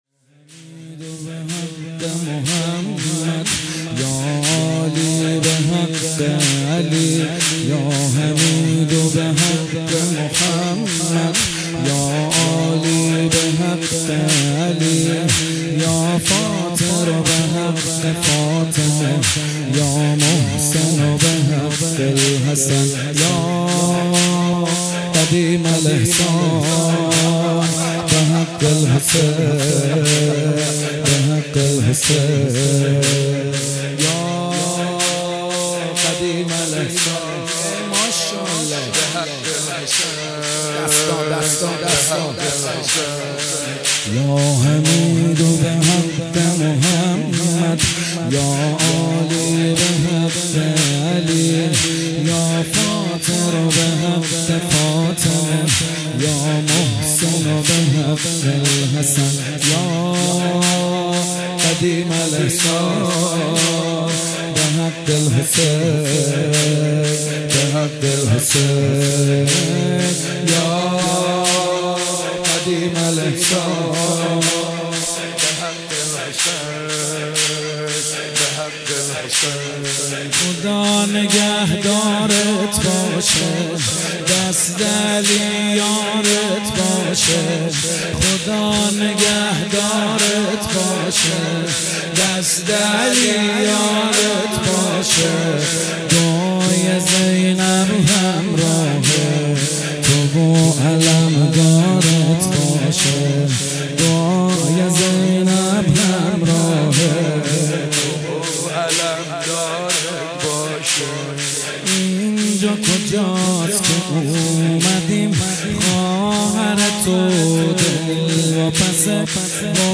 مداحی جدید محمد حسین حدادیان شب دوم محرم 99 مهدیه امام حسن مجتبی(ع) تهران